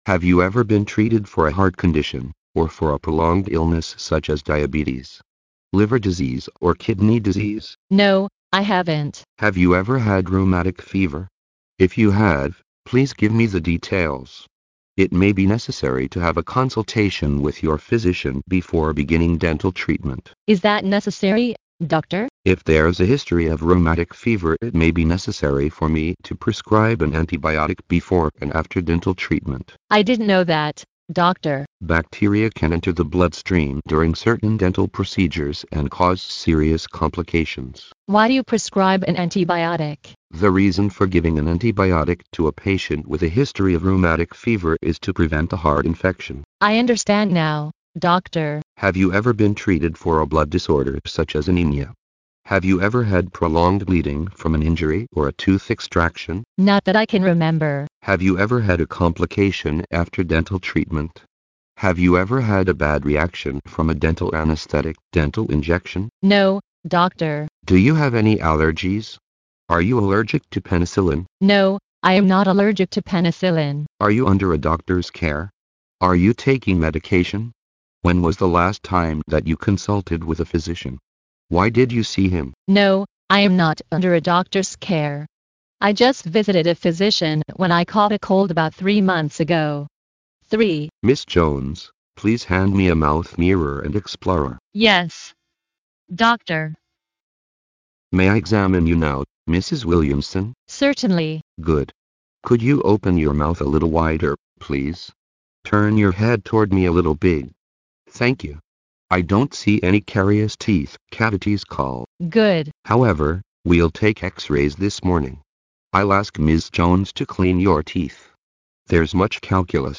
收录了口腔医学医患、医助之间的对话，非常适合医学生、临床医务人员练习专业口语和听力，在欧洲很受欢迎。